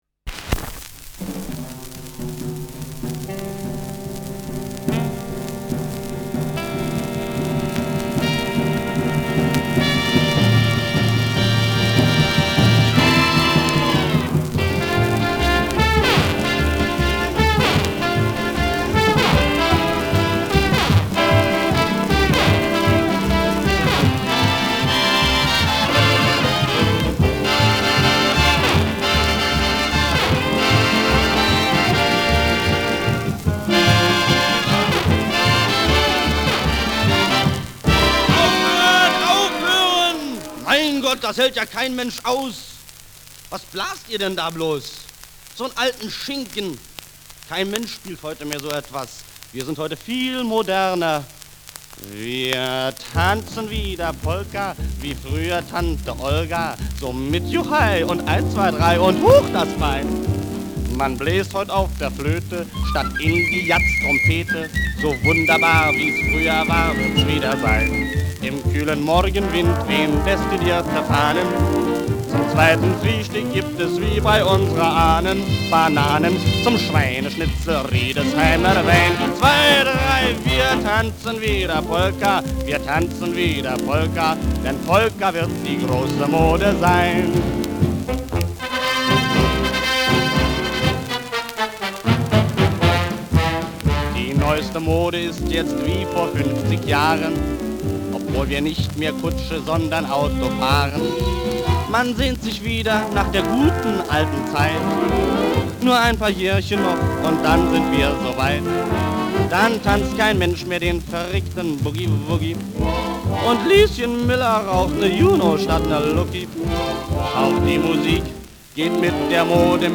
Schellackplatte
Vereinzelt leichtes Knacken
Humoristischer Vortrag* FVS-00003